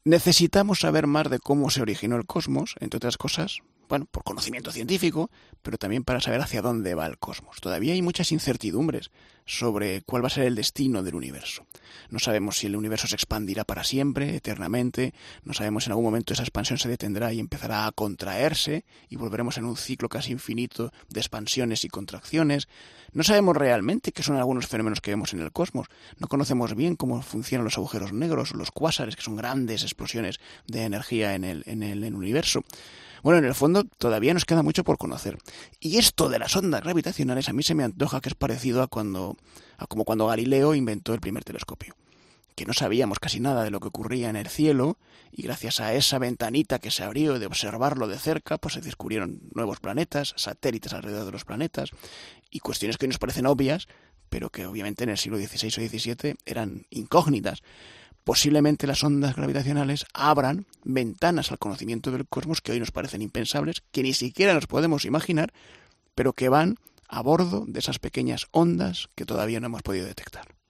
ha visitado el estudio de Poniendo las Calles